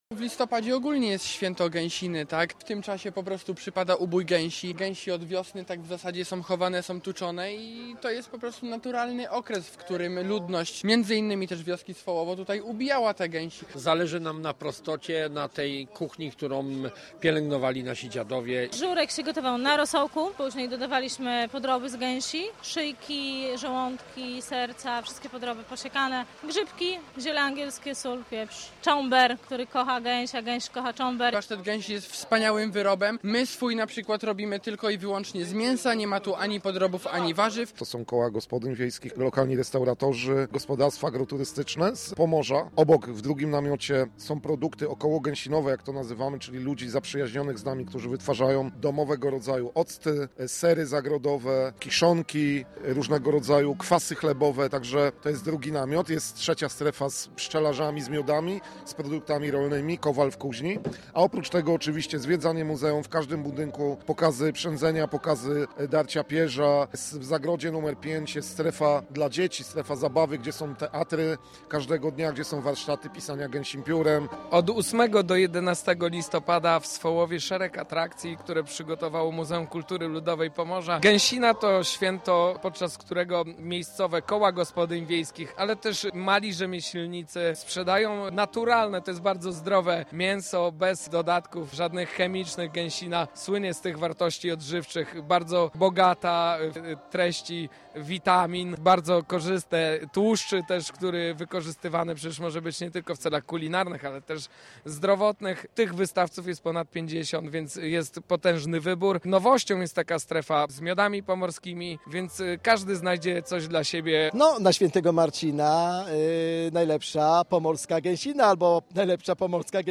Tłumy spacerowiczów, muzyka i unoszące się w powietrzu smakowite aromaty.